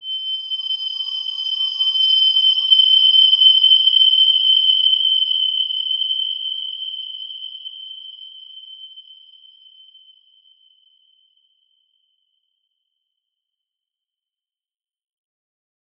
Wide-Dimension-G6-p.wav